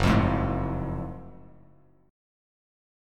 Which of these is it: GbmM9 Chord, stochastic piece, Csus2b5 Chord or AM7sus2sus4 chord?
GbmM9 Chord